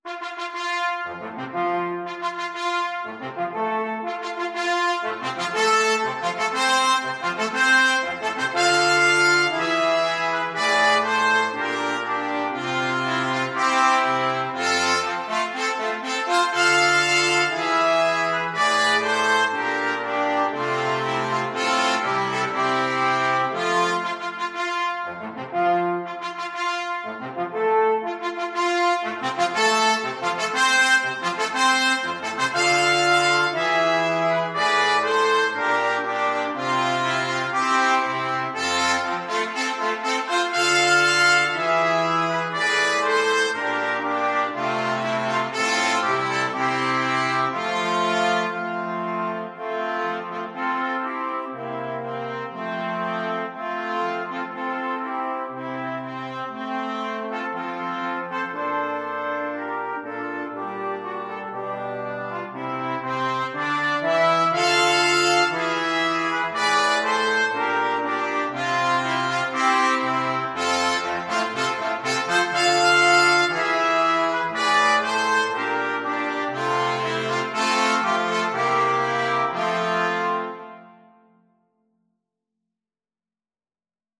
Free Sheet music for Brass Quartet
Trumpet 1Trumpet 2French HornTrombone
F major (Sounding Pitch) (View more F major Music for Brass Quartet )
4/4 (View more 4/4 Music)
Andante maestoso =120
Brass Quartet  (View more Intermediate Brass Quartet Music)
Classical (View more Classical Brass Quartet Music)